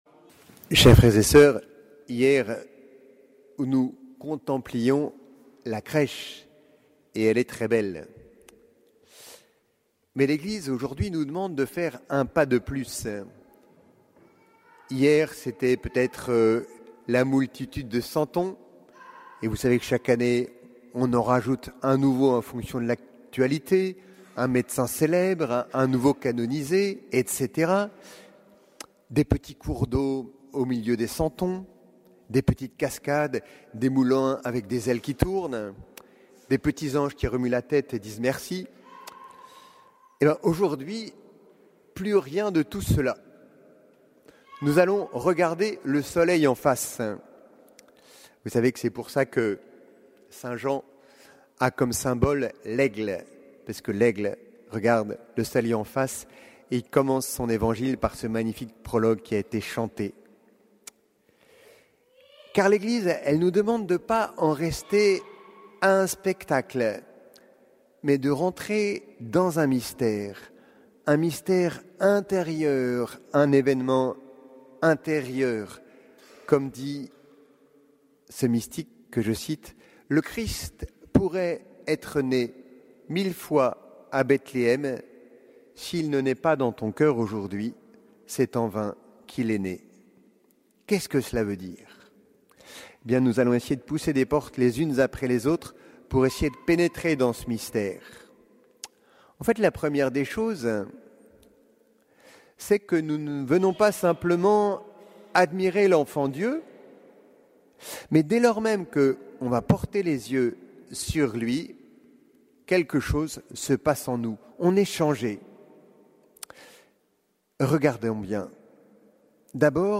Homélie de la solennité de la Nativité du Seigneur (messe du jour)